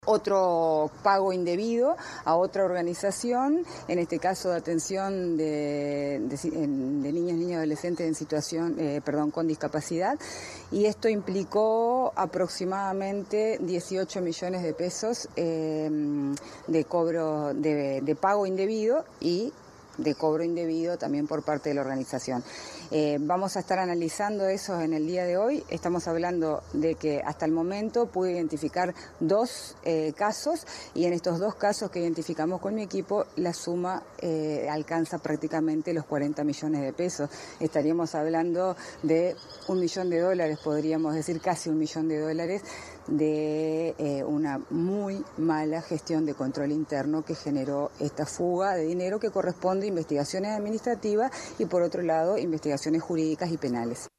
En diálogo con MVD Noticias la directora de INAU, explicó el trabajo que realizó con sus equipos técnicos para llegar a esta cifra, mientras que el presidente, de la institución Guillermo Fossati, pidió revisar las cifras.